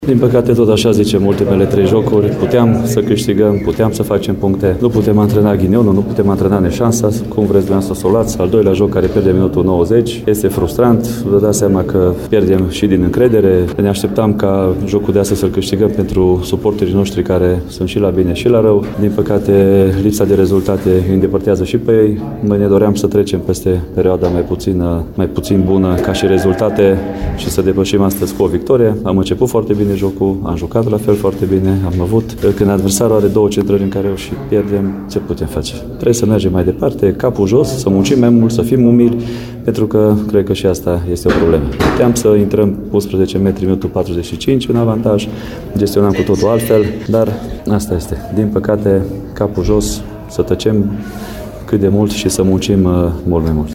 s-a arătat extrem de supărat pentru deznodământul partidei: